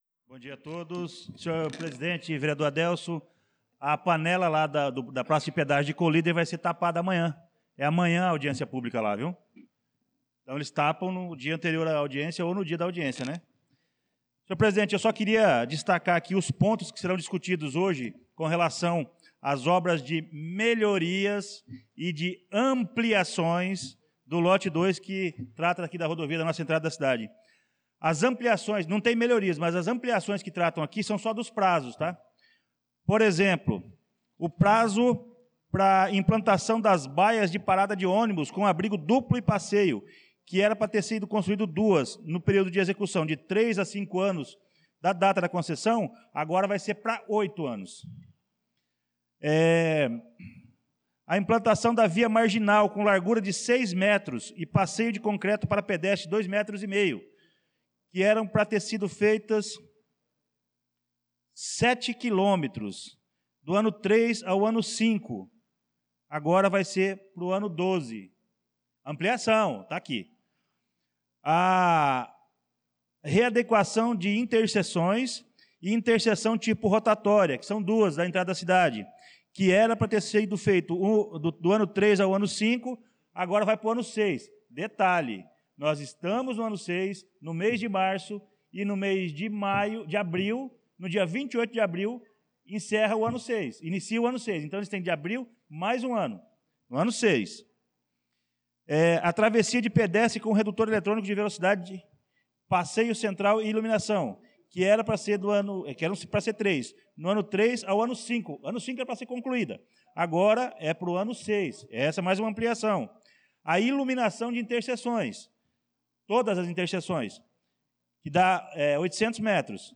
Pronunciamento do vereador Luciano Silva na Sessão Ordinária do dia 18/03/2025